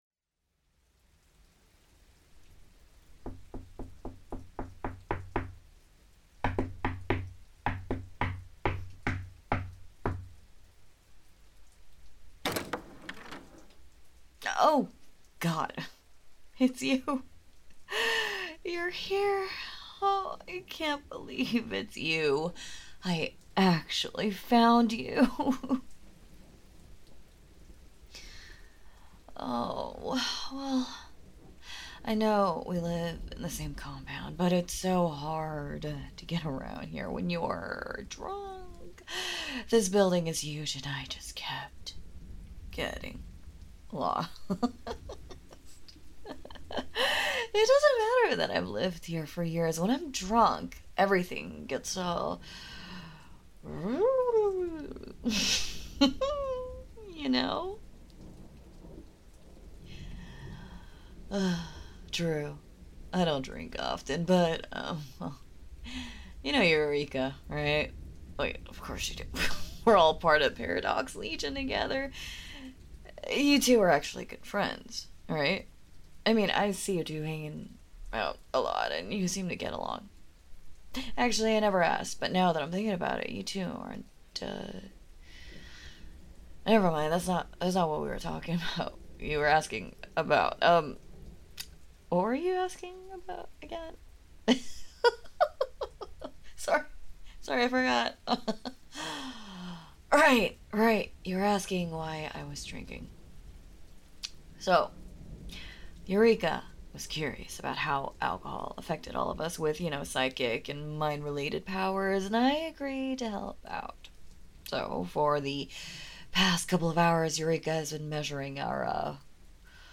Not sleep aid, because the Speaker is "drunk" AF (not really, obvs, duh) but more long audio roleplay than anything else.